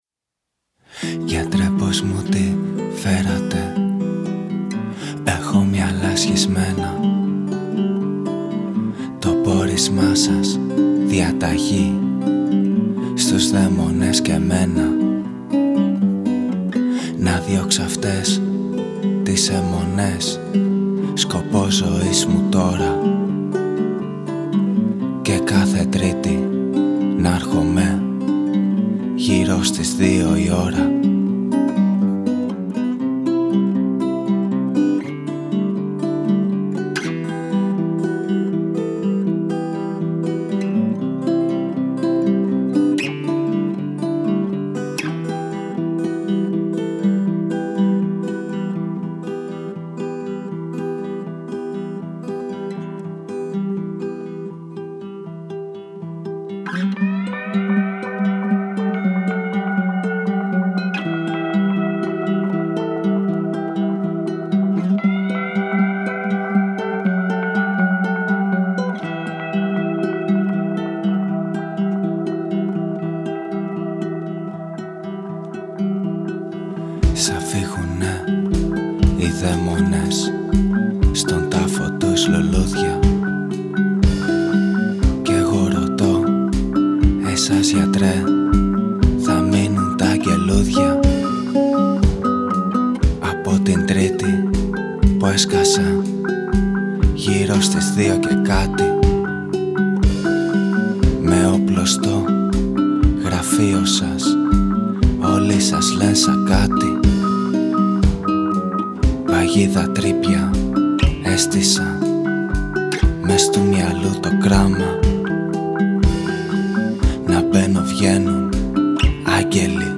Τα τύμπανα